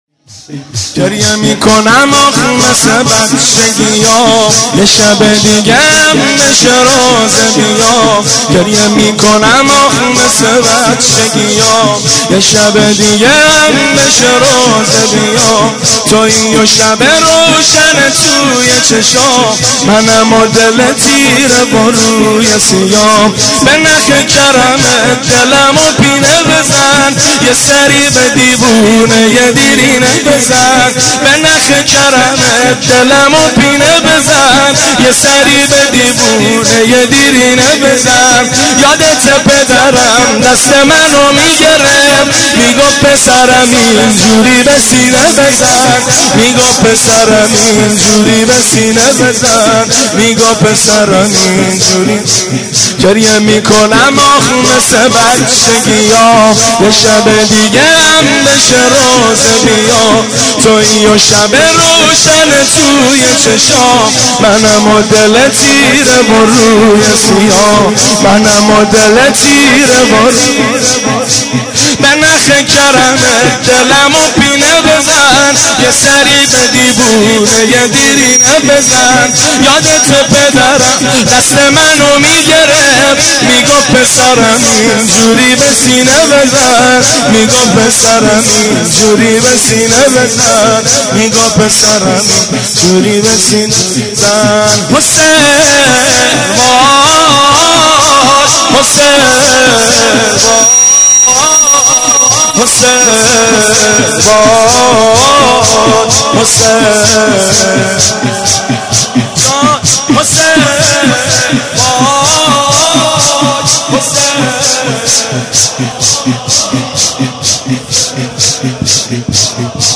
دانلود مداحی جدید